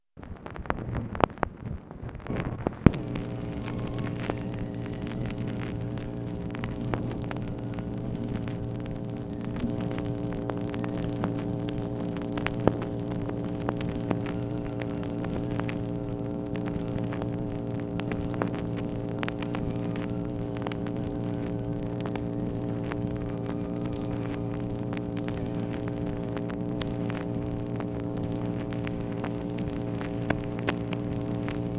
OFFICE NOISE, August 25, 1964
Secret White House Tapes | Lyndon B. Johnson Presidency